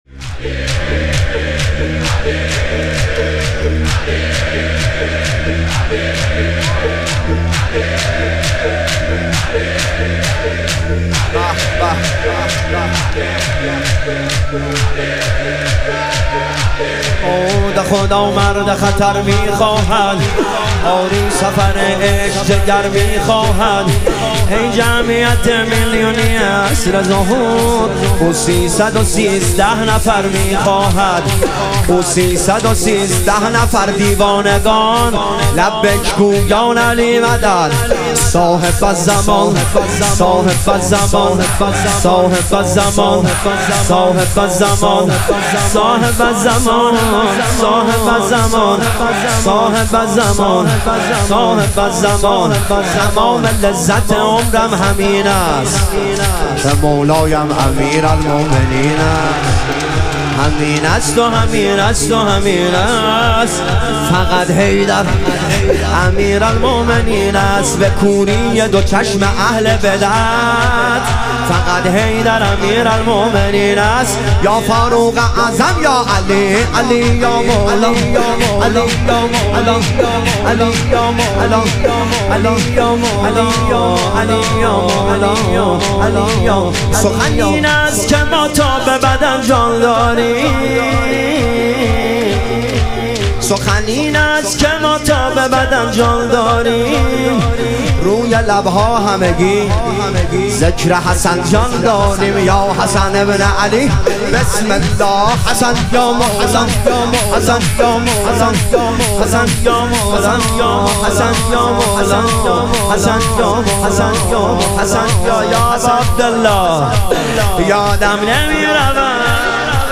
شور
شب شهادت امام هادی علیه السلام